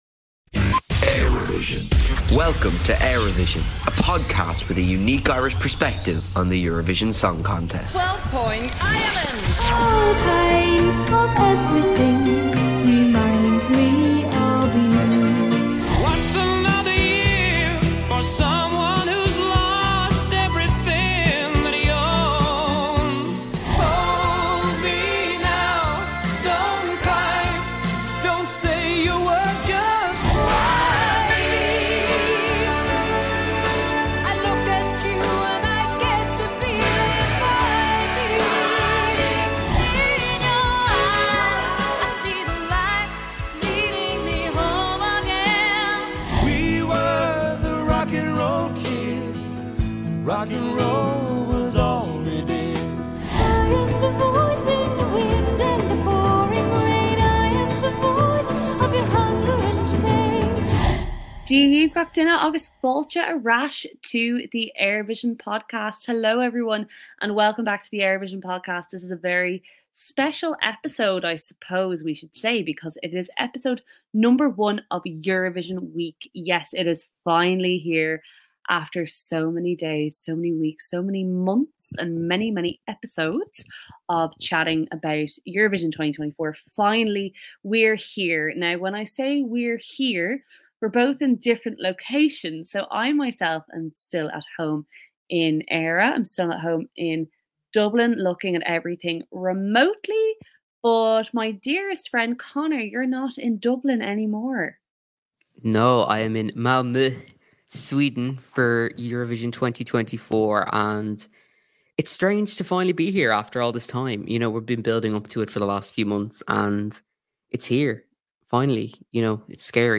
Live from Malmö: Our final Semi-Final 1 predictions!